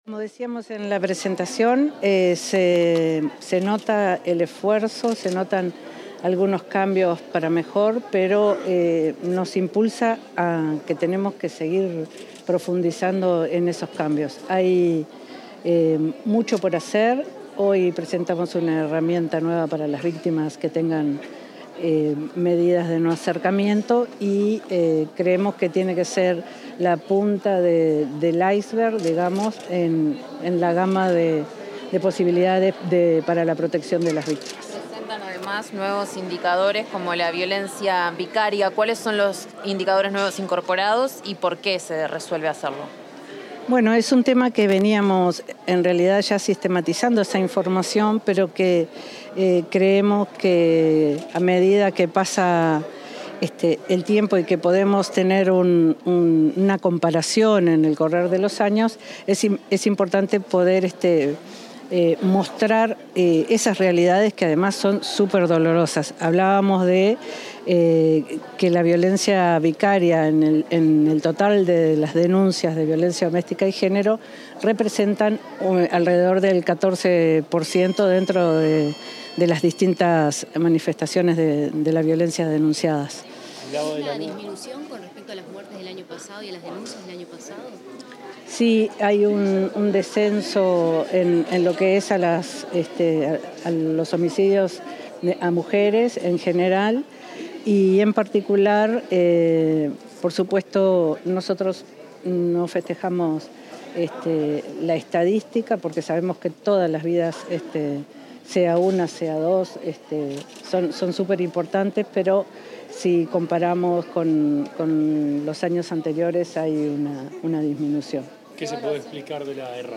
Declaraciones de la directora de Género del Ministerio del Interior, July Zabaleta